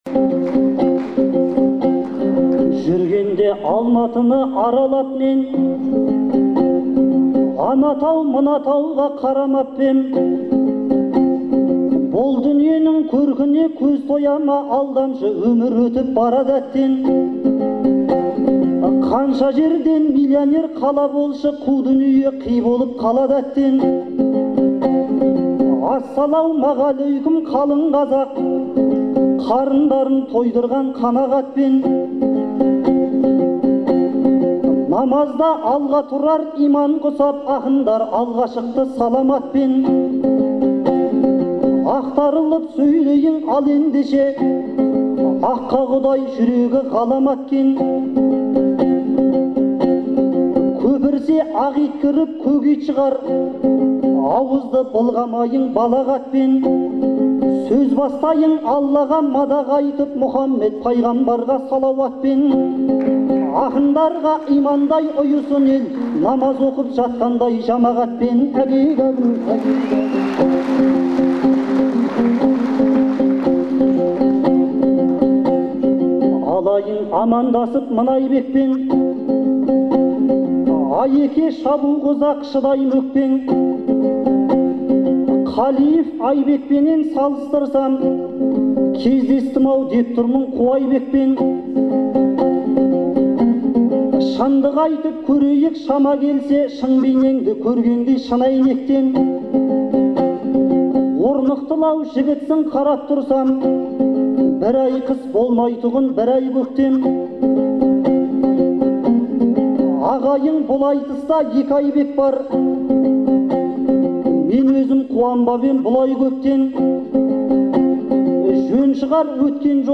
Мамырдың 2-сі күні Алматыда өткен «Төртеу түгел болса» атты айтыстың екінші жұбы